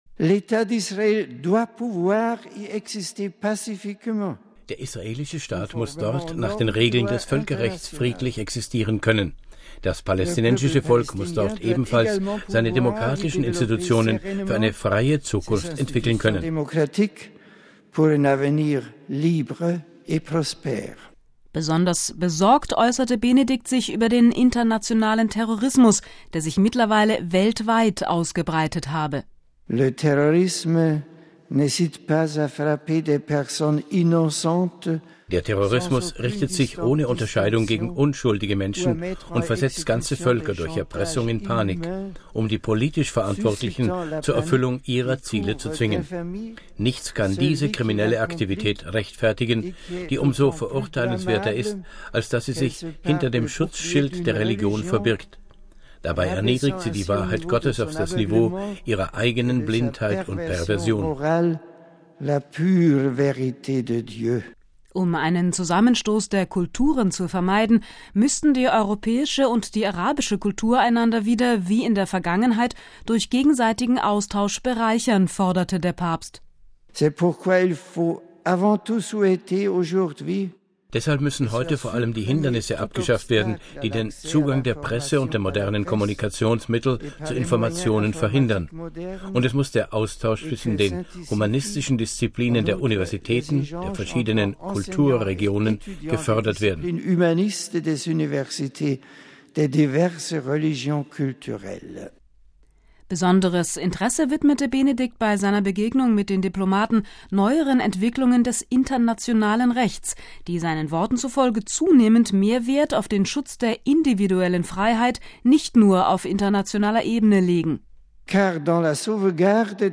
Papst Benedikt XVI. hat heute die bisher politischste Ansprache seit seinem Amtsantritt im vergangenen April gehalten. Bei der Neujahrsaudienz für die beim Heiligen Stuhl akkreditierten Diplomaten mahnte er zu verstärktem Einsatz für den Frieden.